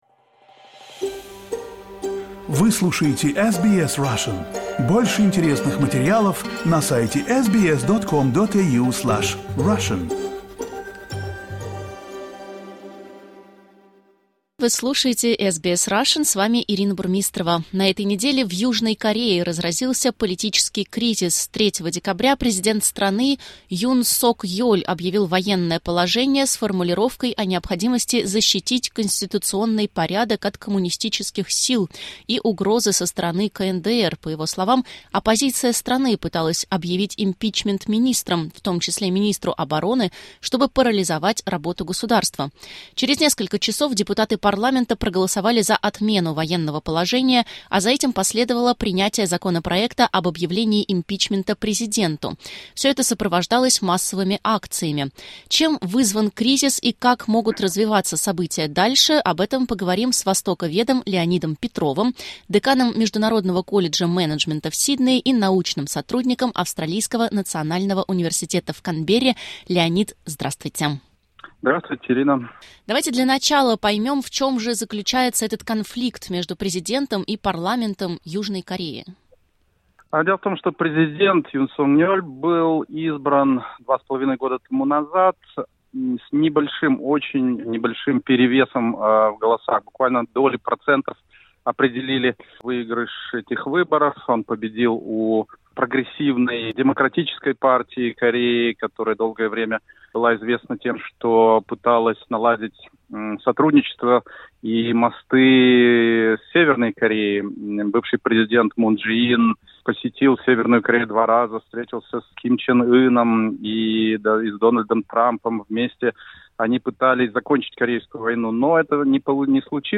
Чем вызван кризис и как могут развиваться события дальше? Рассказывает востоковед